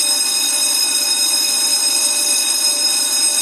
alarmClicheLoop.ogg